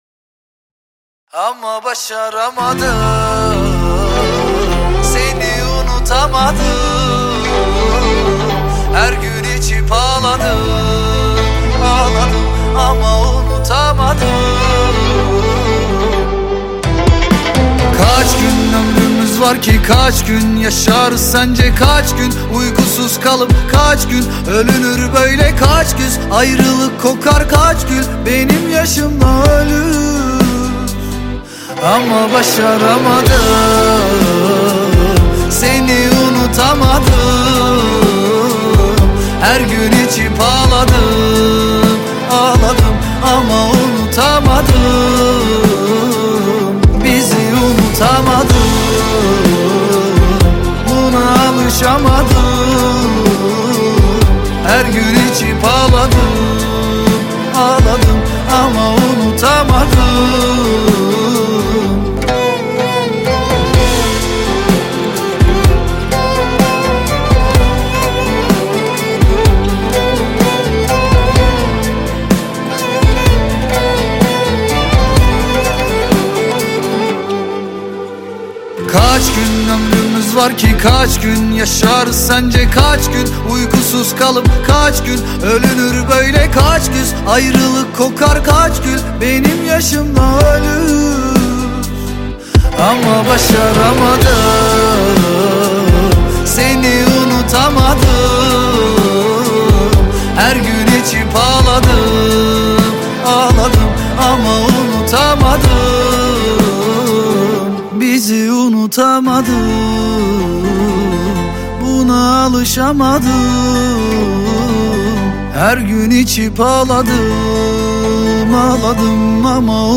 With his strong vocals and sincere verses